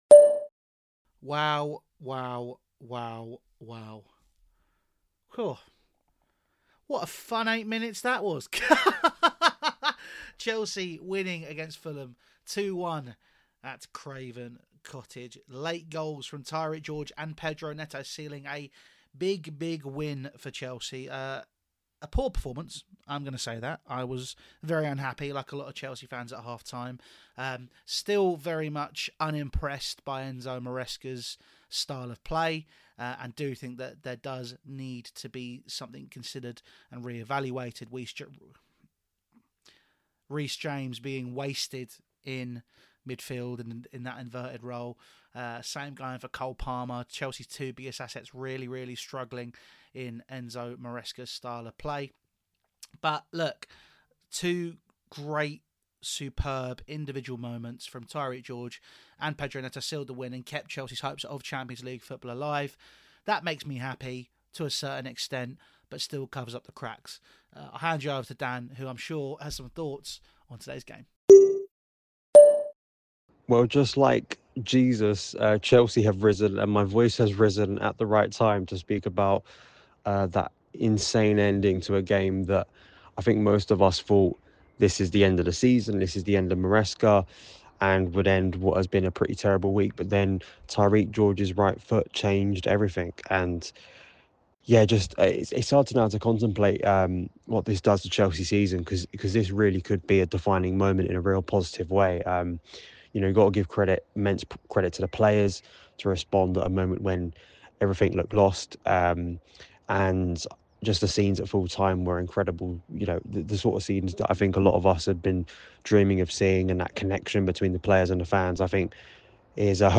| Fulham 1-2 Chelsea Voicenote Review